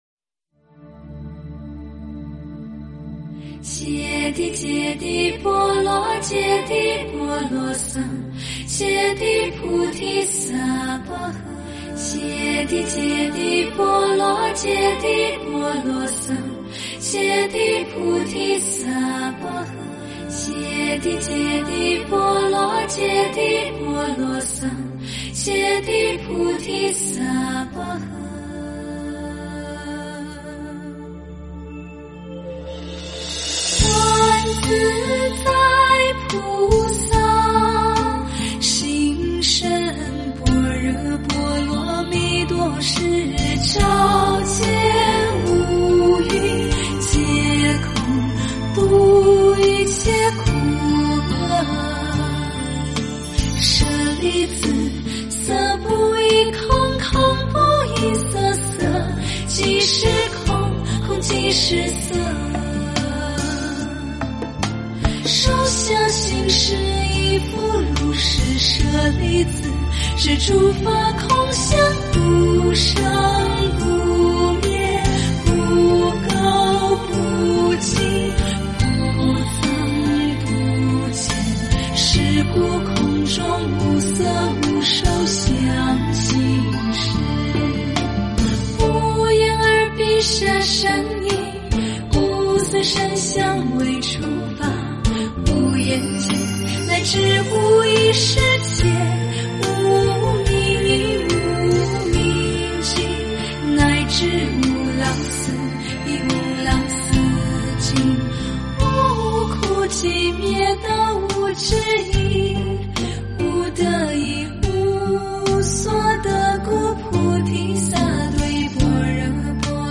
般若波罗蜜心经--佚名 经忏 般若波罗蜜心经--佚名 点我： 标签: 佛音 经忏 佛教音乐 返回列表 上一篇： 《妙法莲花经》法师功德品 第十九--佚名 下一篇： 《妙法莲华经》嘱累品第二十二--佚名 相关文章 佛宝赞--寺院唱颂版1 佛宝赞--寺院唱颂版1...